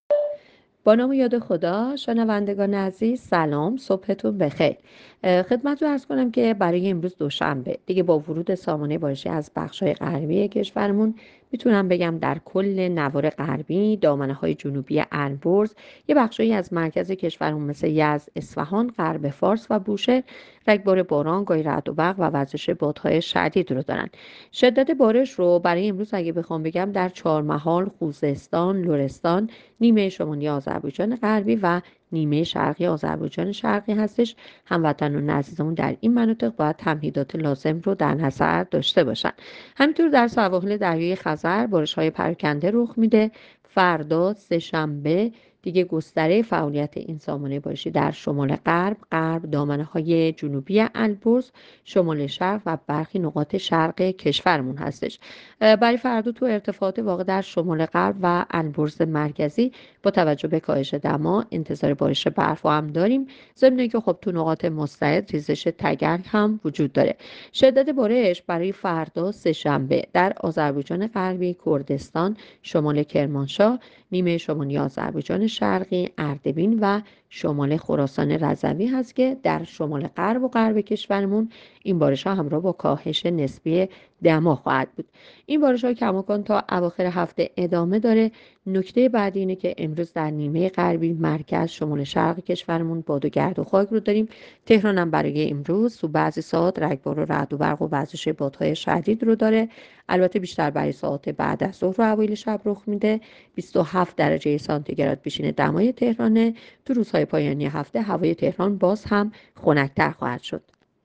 گزارش رادیو اینترنتی پایگاه‌ خبری از آخرین وضعیت آب‌وهوای ۲۵ فروردین؛